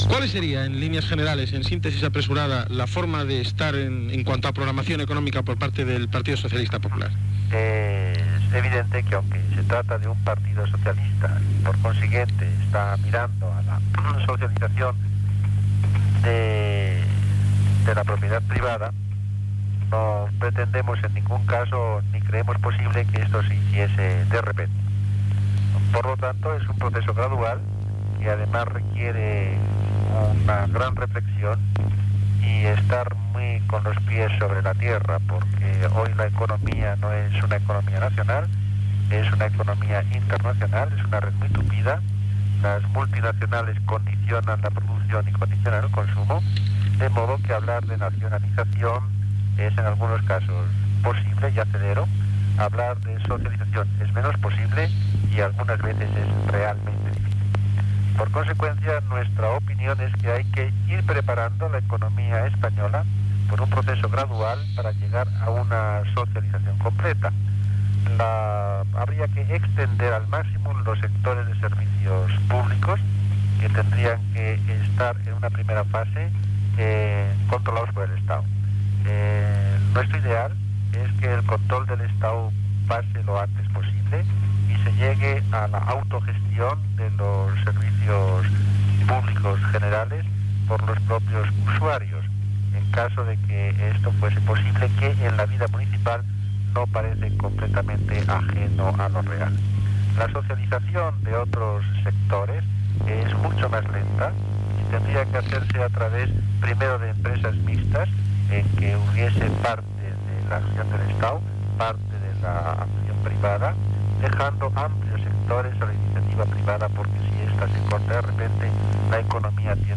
Entrevista a Enrique Tierno Galván, del Partido Socialista Popular
Informatiu